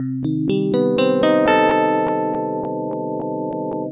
The Visitor Cm 122.wav